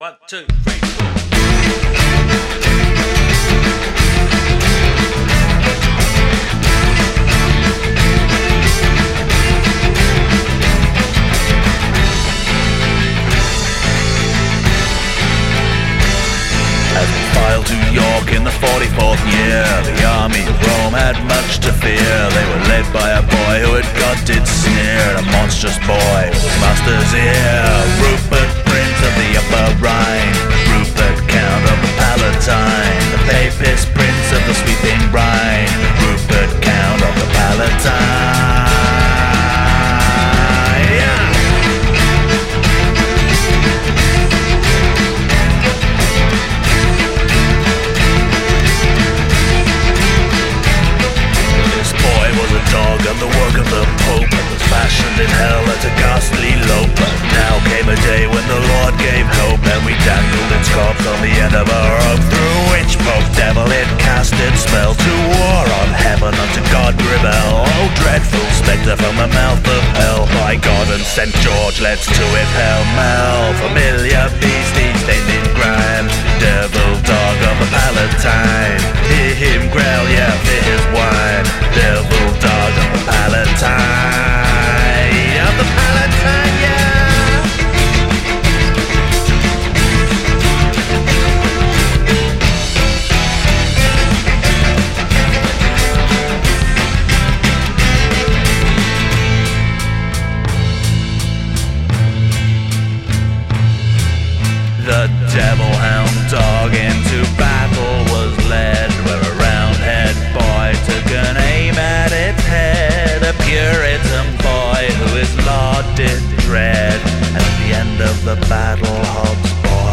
a Levellers-style band